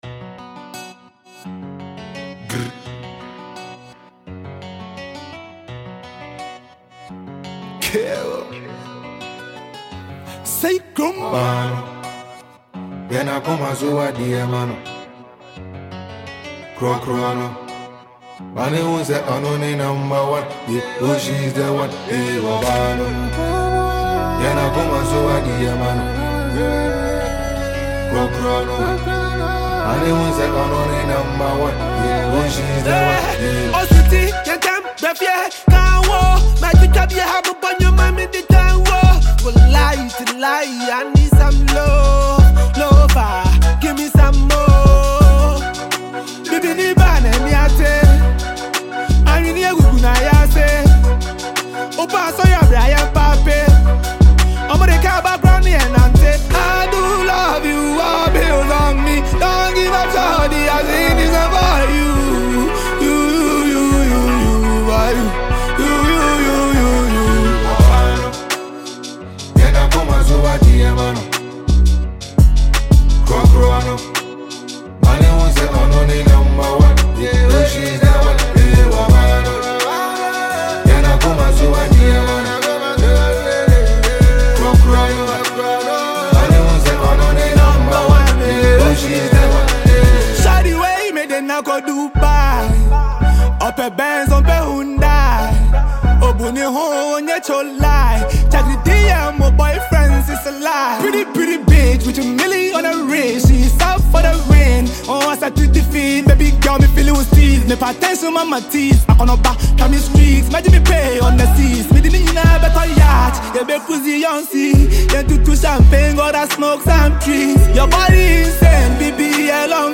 Hip Hop jam